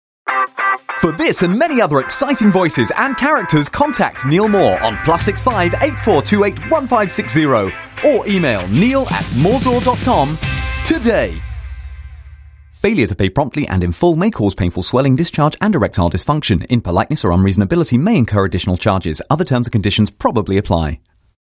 Voice Samples: Reel Sample 02
male
EN UK